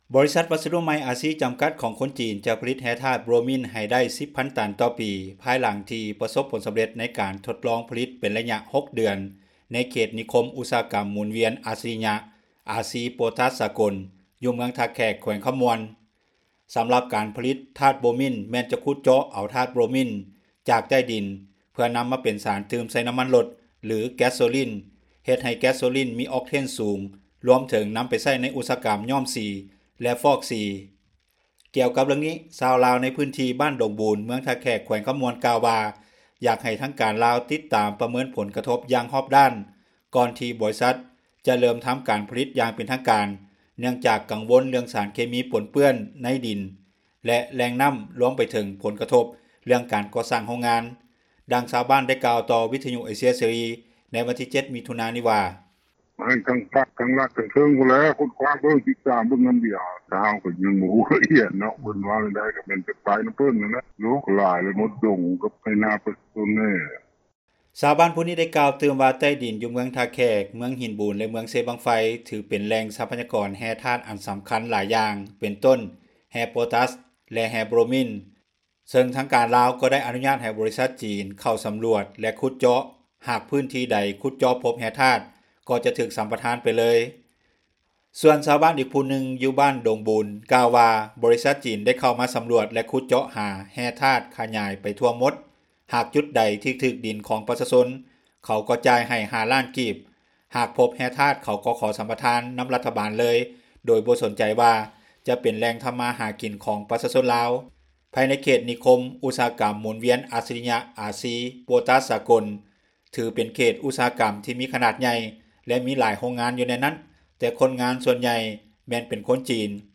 ດັ່ງຊາວບ້ານ ກ່າວຕໍ່ວິທຍຸ ເອເຊັຽ ເສຣີ ໃນວັນທີ 07 ມິຖຸນານີ້ ວ່າ:
ດັ່ງເຈົ້າໜ້າທີ່ ກ່າວຕໍ່ວິທຍຸ ເອເຊັຽ ເສຣີ ໃນມື້ດຽວກັນນີ້ວ່າ: